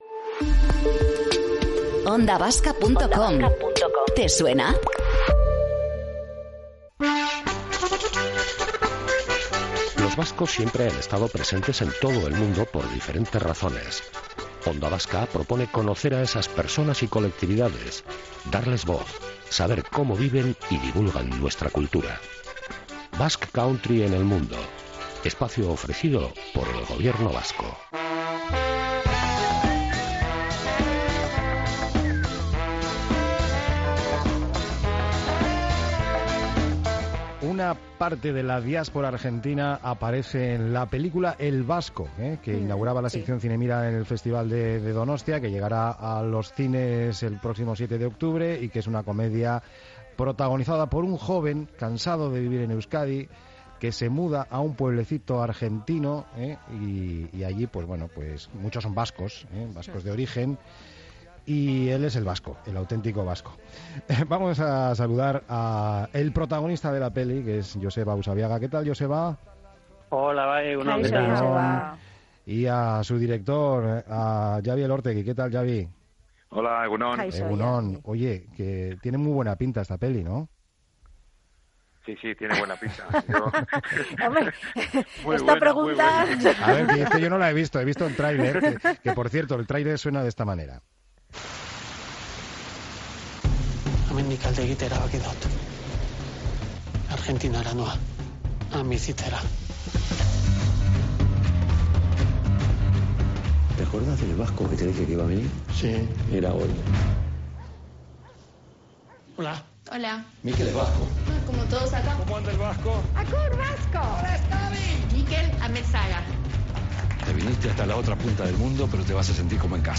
Tomando el pulso a la actualidad en el mediodía más dinámico de la radio.